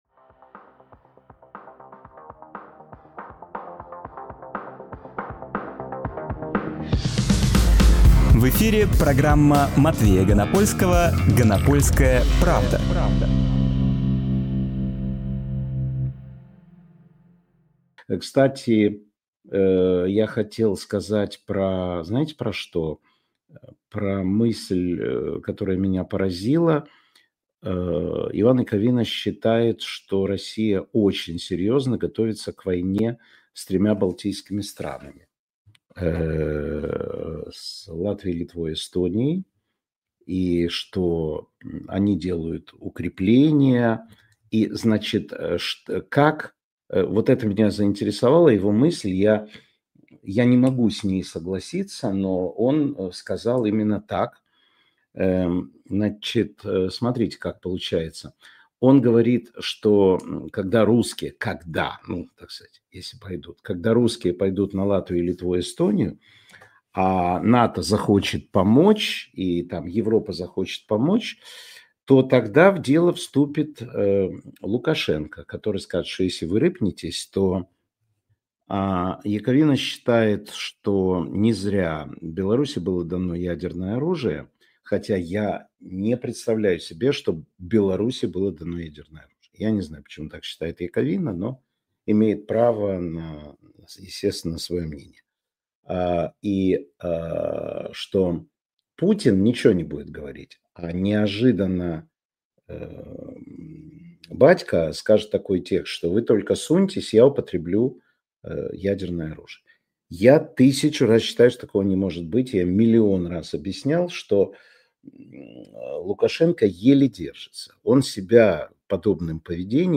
Матвей Ганапольскийжурналист
Фрагмент эфира от 02.05.25